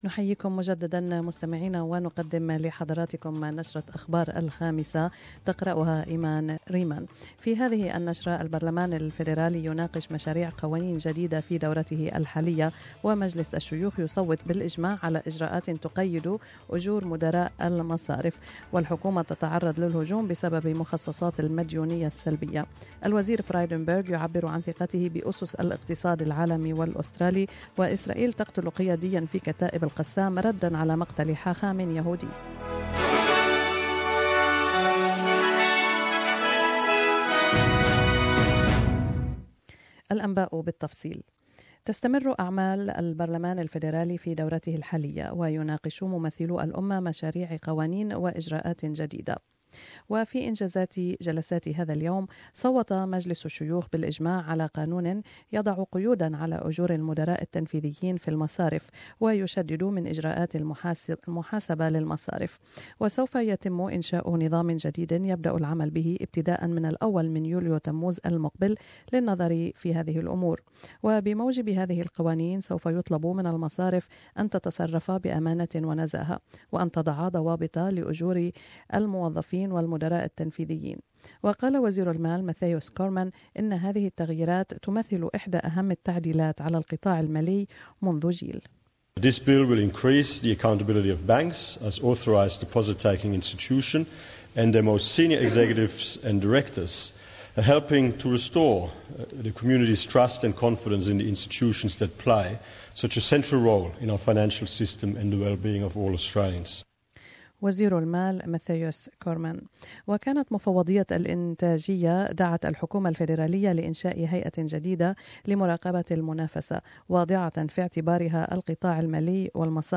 The news today National security issues, asylum seeker management and economic initiatives lead parliamentary debate in Canberra today. But it was a tearful federal Labor M-P Susan Lamb who shared an intensely personal story to explain her dual citizenship woes.